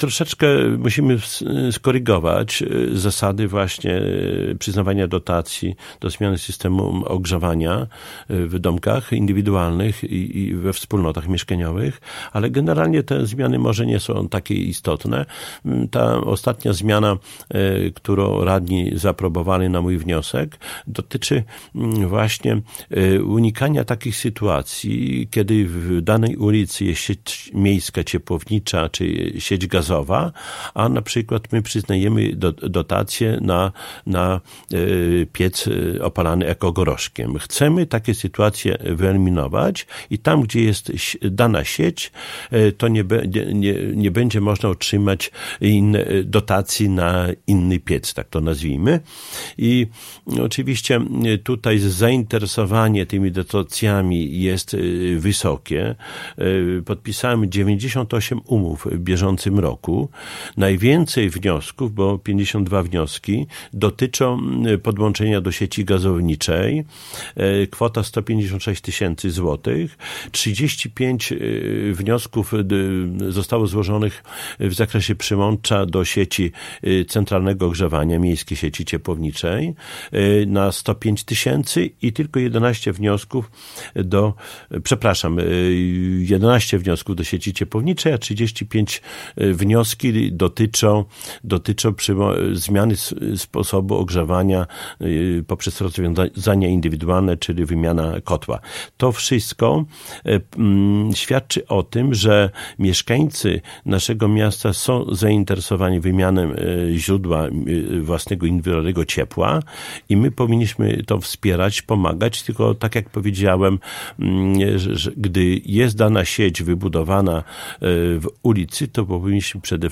– Program dopłat do wymiany źródeł ciepła cieszy się wśród mieszkańców dużą popularnością. Dlatego będziemy go kontynuować. To dobre samorządu w kierunku skutecznego przeciwdziałania zjawisku niskiej emisji popularnie zwanej smogiem – mówił w piątek (30.06) w Radiu 5 Czesław Renkiewicz, Prezydent Suwałk.